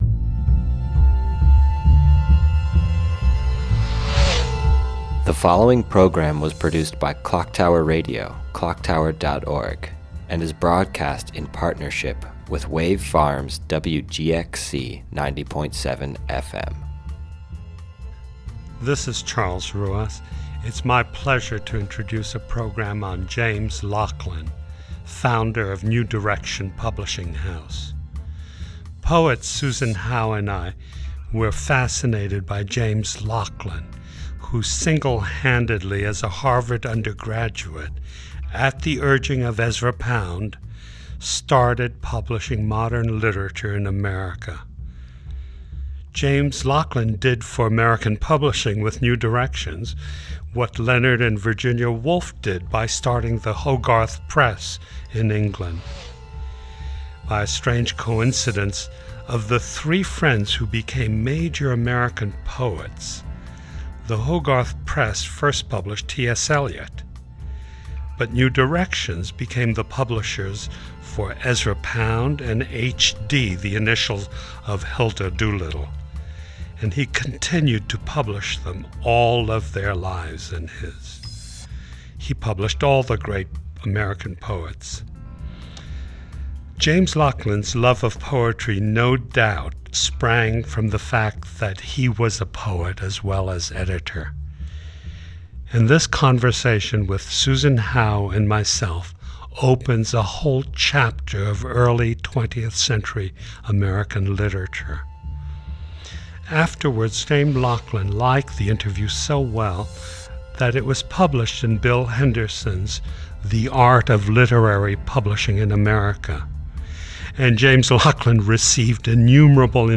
Historic Audio